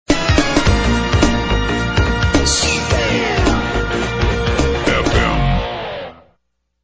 All tracks encoded in mp3 audio lo-fi quality.